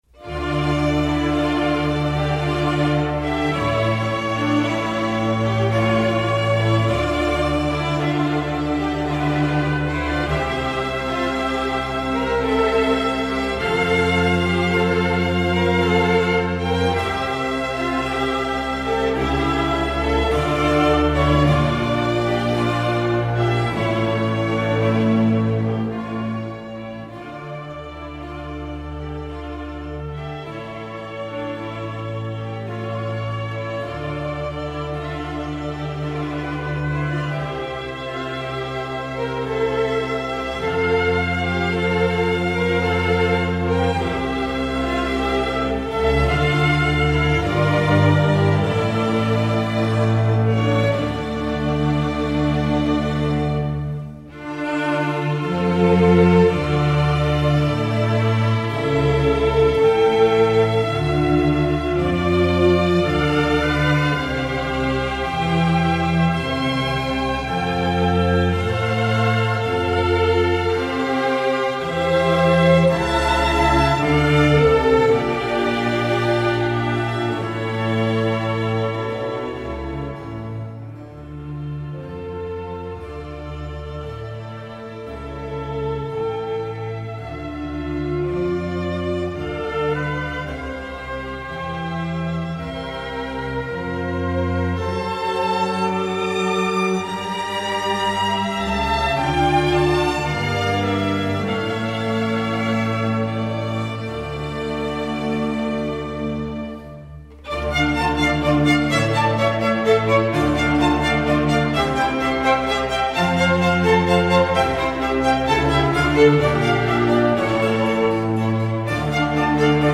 Geminiani: Concerto Grosso 12 in d minor La Follia